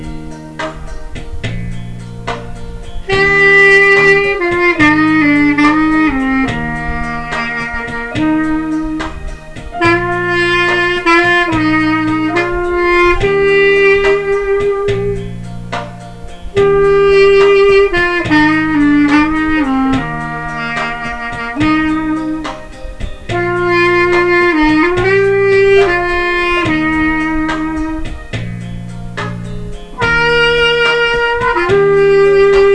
Coz it squeak easily especially on higher octave.
clarinet01.wav